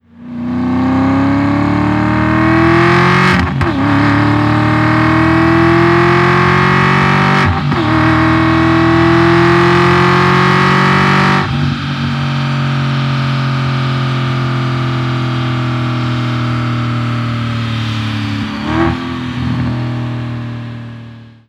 Realistischer Klang: Erleben Sie echten Auspuffsound per Knopfdruck, ein- und ausschaltbar nach Belieben.